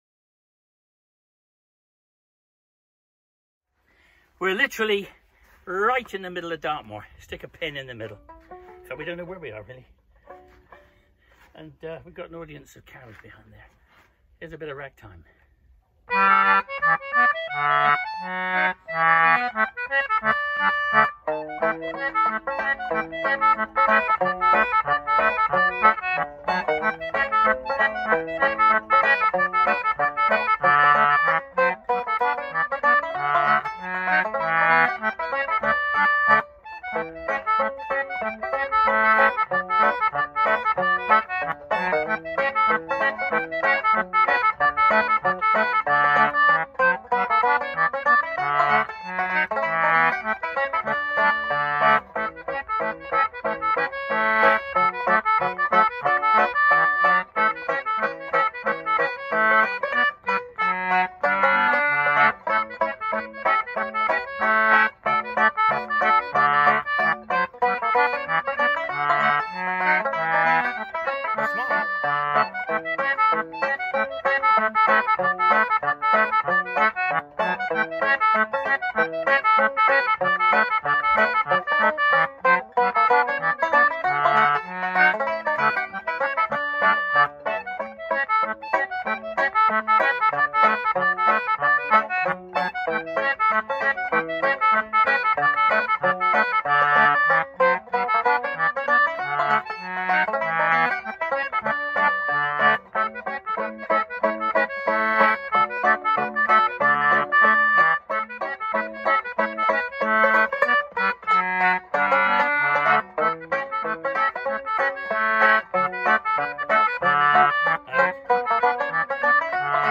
banjo